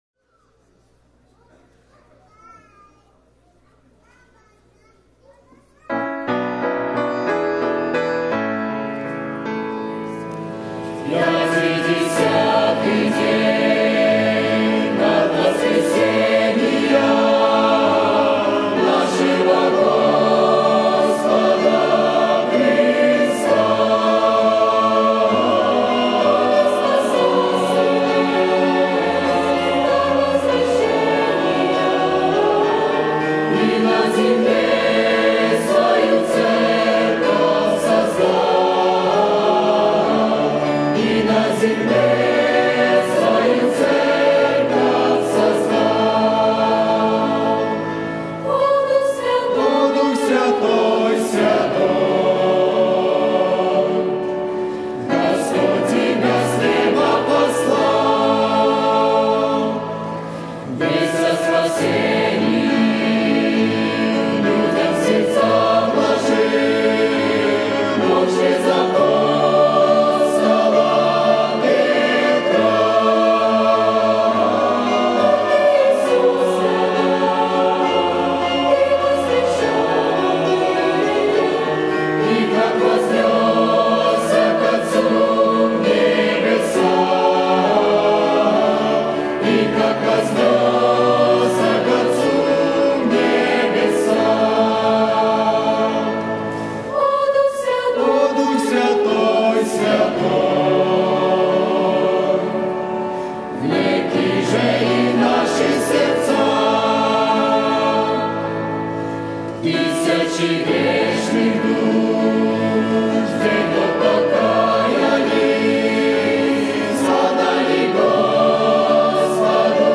110 просмотров 124 прослушивания 7 скачиваний BPM: 100